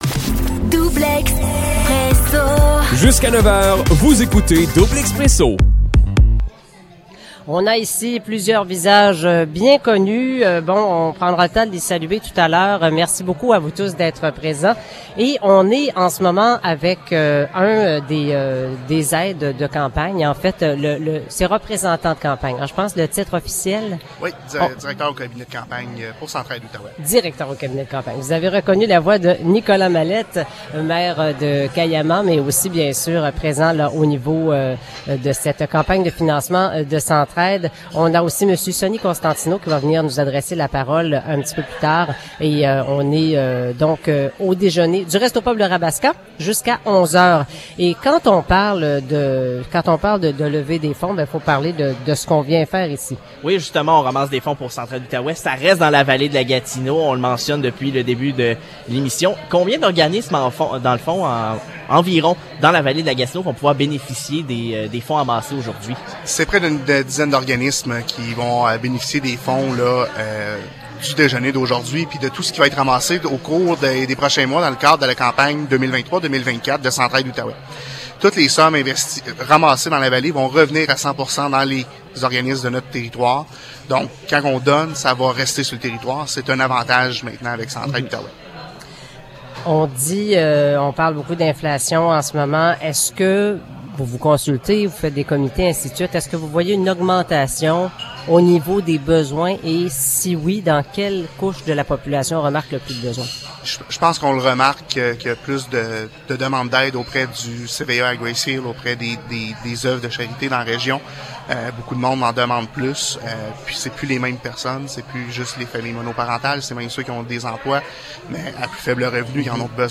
Entrevue
en direct du déjeuner de la préfète